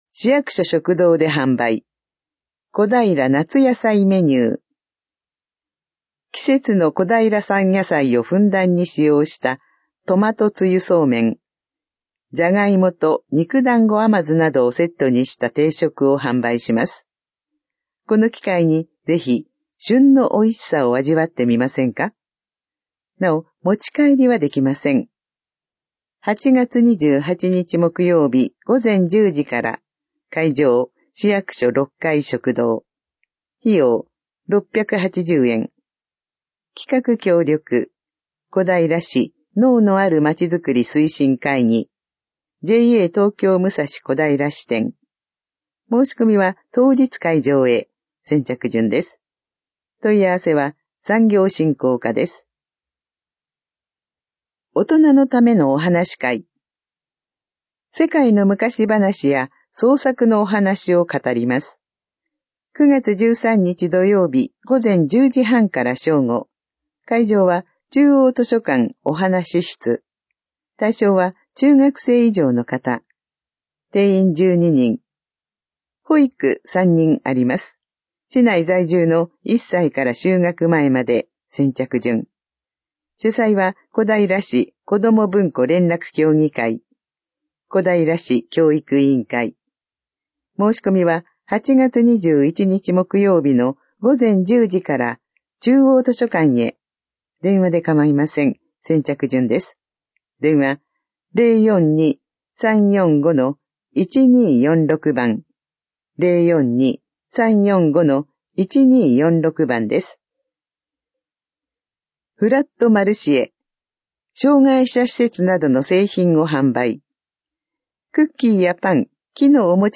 市報こだいら2025年8月20日号音声版